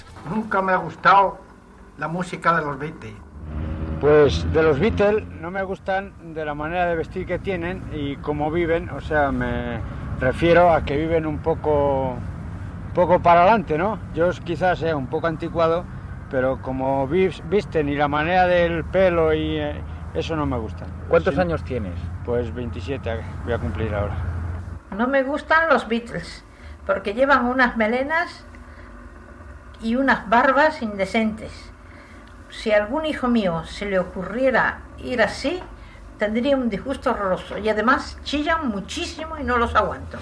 Testimonis de ciutadans espanyols sobre l'aspecte dels inegrants del grup musical The Beatles.
Informatiu